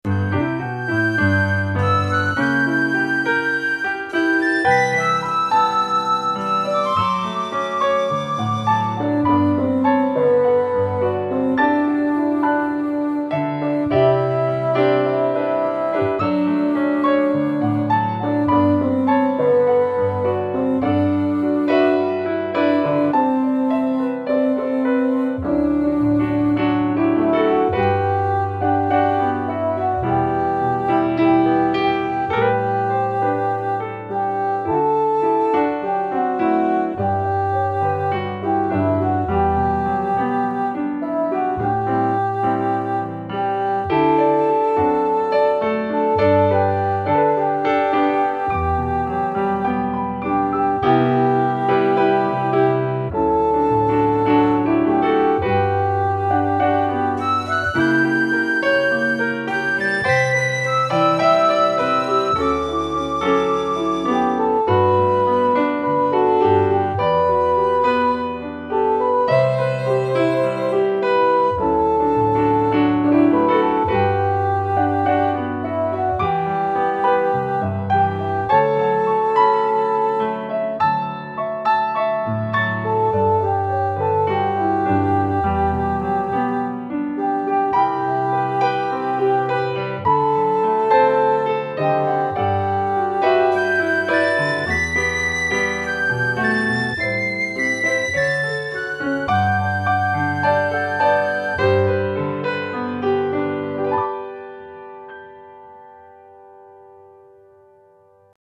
I just make backings.